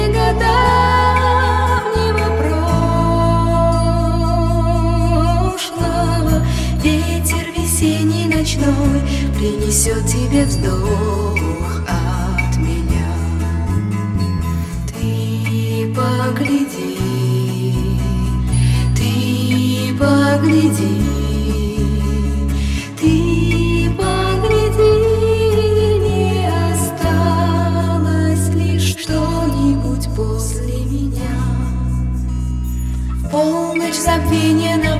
Жанр: Музыка из фильмов / Саундтреки / Русские